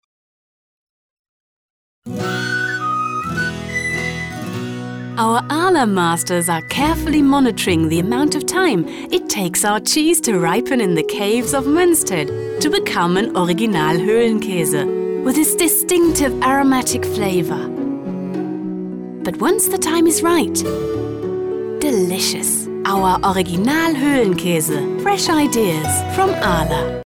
Sprecherin / Schauspielerin in Englisch und Deutsch
Kein Dialekt
Sprechprobe: Sonstiges (Muttersprache):